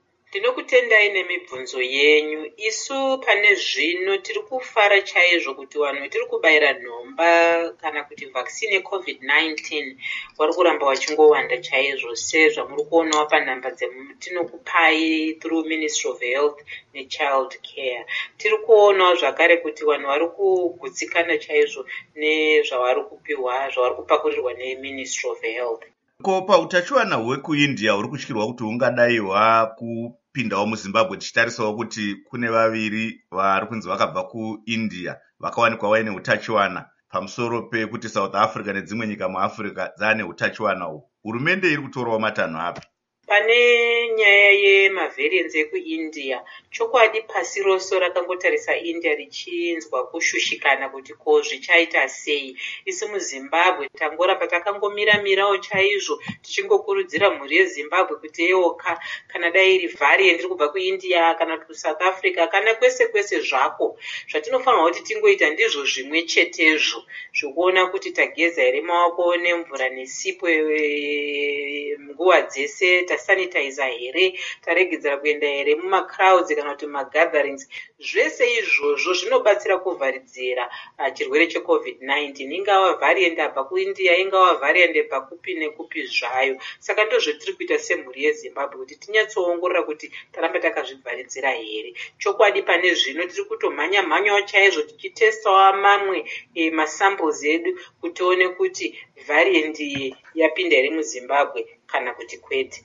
Hurukuro naDr.